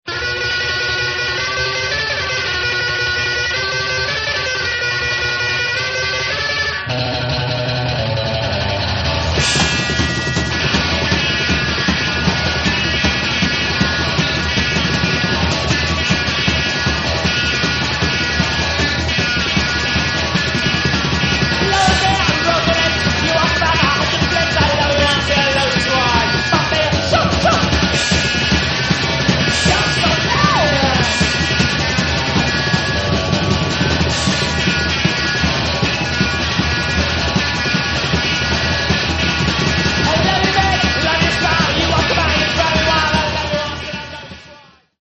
1999 nervous fast voc.